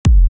Kicks
SSL Thwack.wav